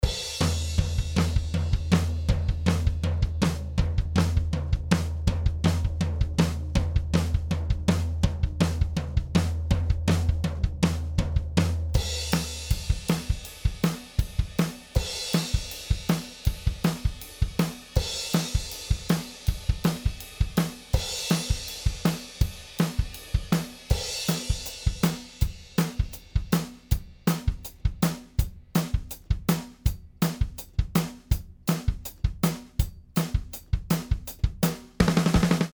B172A 17 into 2A Drums